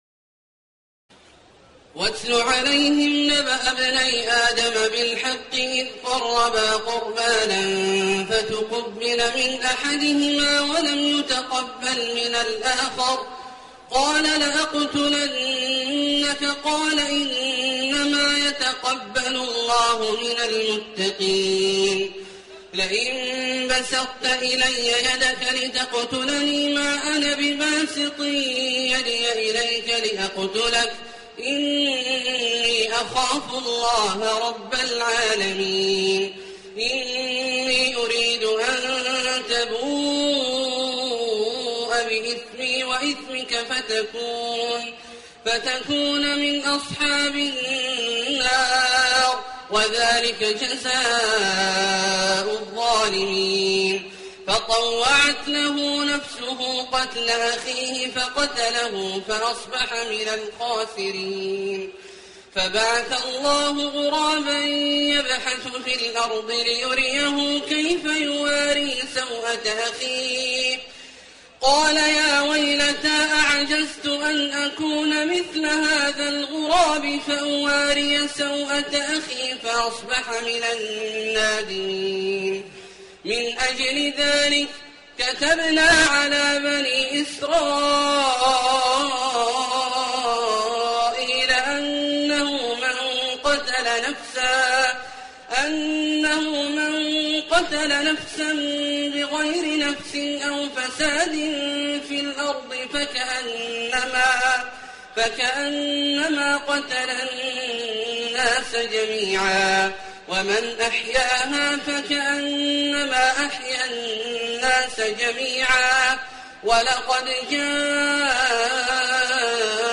تراويح الليلة السادسة رمضان 1429هـ من سورة المائدة (27-50) Taraweeh 6 st night Ramadan 1429H from Surah AlMa'idah > تراويح الحرم المكي عام 1429 🕋 > التراويح - تلاوات الحرمين